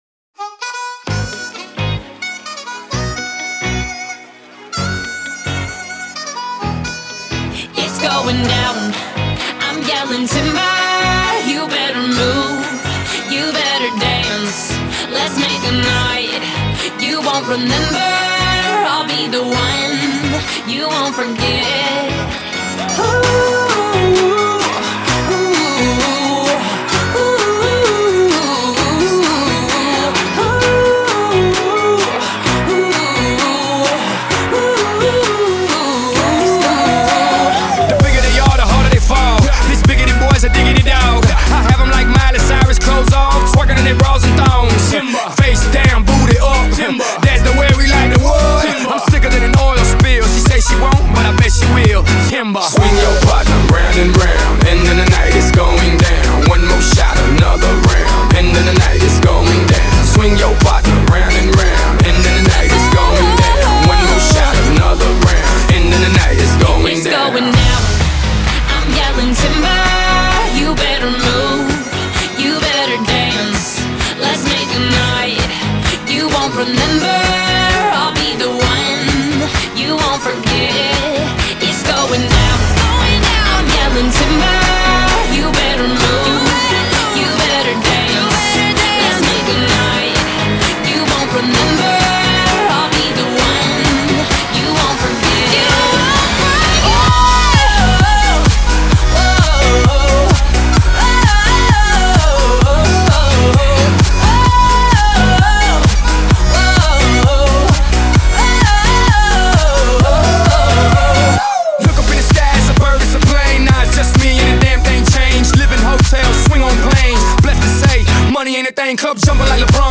〽 ژانر Electropop